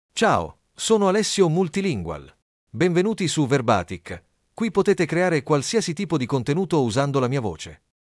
Alessio MultilingualMale Italian AI voice
Voice sample
Male
Alessio Multilingual delivers clear pronunciation with authentic Italy Italian intonation, making your content sound professionally produced.